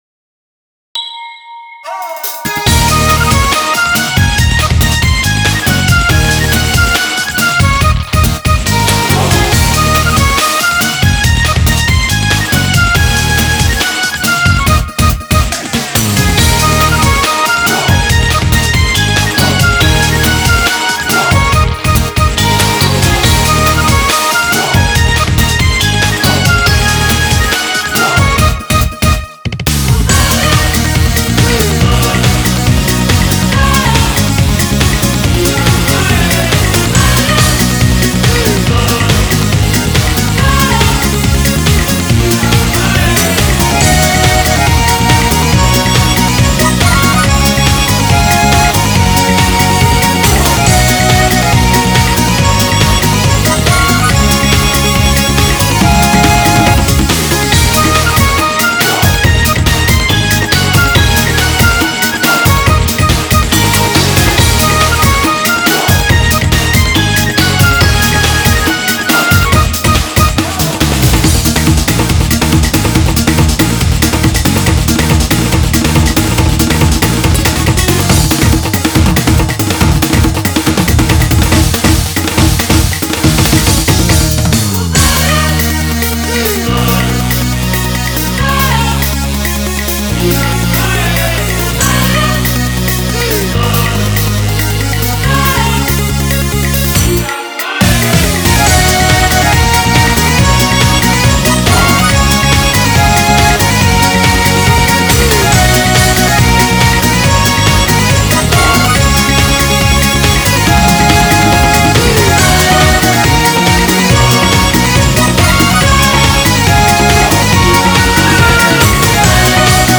BPM140
Audio QualityPerfect (High Quality)